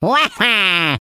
One of Waluigi's voice clips in Mario Kart: Double Dash!!